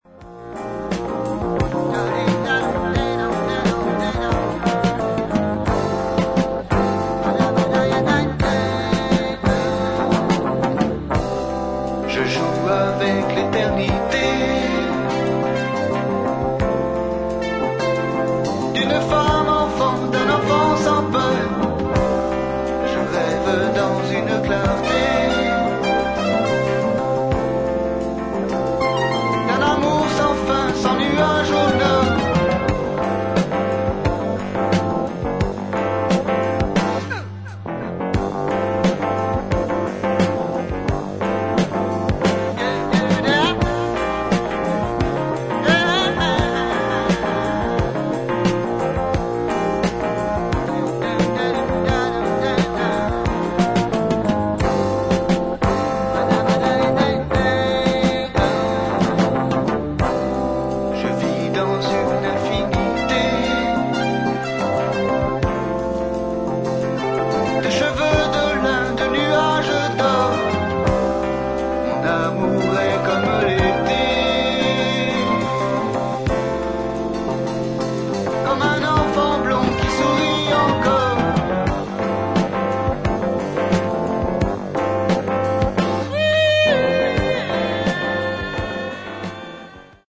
各プレイヤーのスキルが際立つ、ソウルフルでファンキーな名演を披露しています。